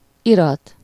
Ääntäminen
France: IPA: /dɔ.ky.mɑ̃/